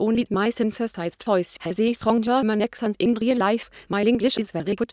CHATR's German synthesis)